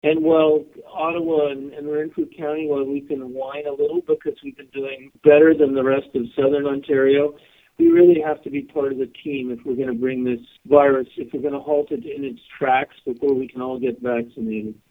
Cushman says while Renfrew County and Ottawa have not been as hard hit as the Toronto area, it’s important we are all on the same page to halt the spread of the virus before we can get vaccinated.